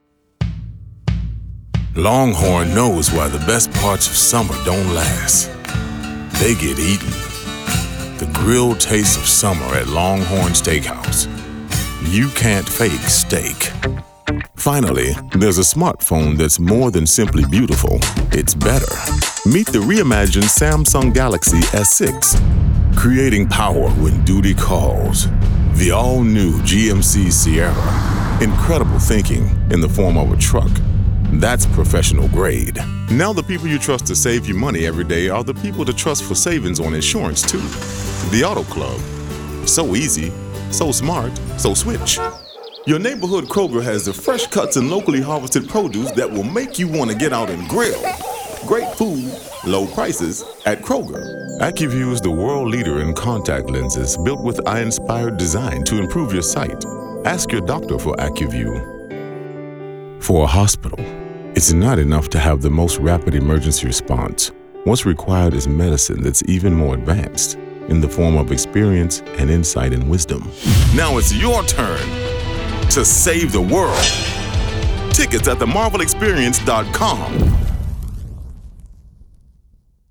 He's unique in that he sounds gravelly & distinctive, but has the rare talent of sounding authentic/real.
1115Commercial_Demo.mp3